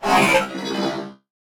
ogg / general / combat / enemy / droid / hurt2.ogg
hurt2.ogg